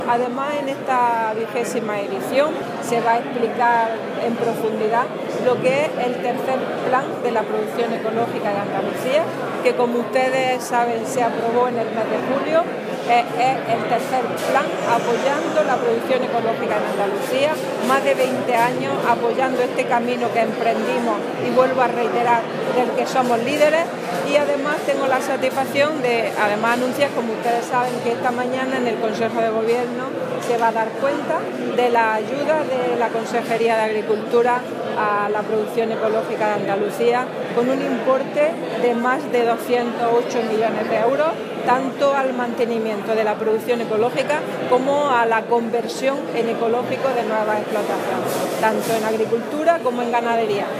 Declaraciones Carmen Ortiz sobre BioCórdoba (2)